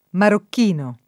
marokk&no] (antiq. marrocchino [marrokk&no]) etn. — ancóra usabile la forma con -r- doppia come s. m. («cuoio»): religiosamente legato in marrocchino rosso [